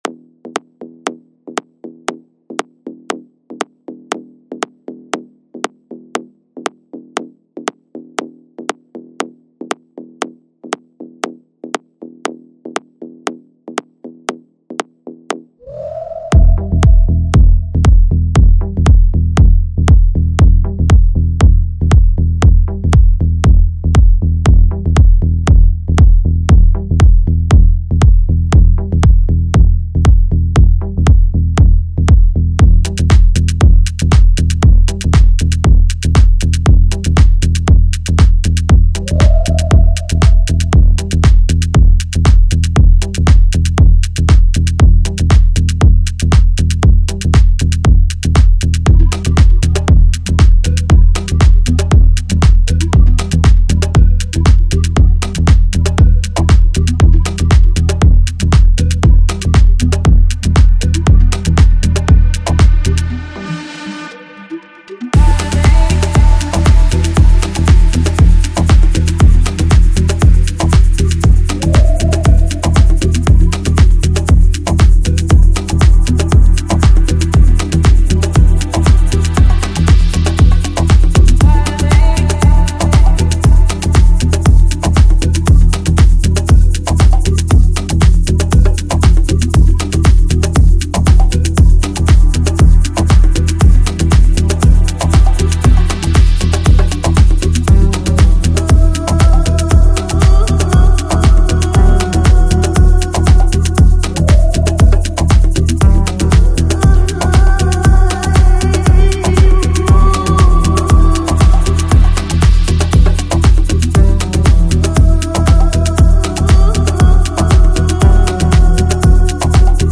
Lounge, Chill Out, Deep House, Ethnic Продолжительность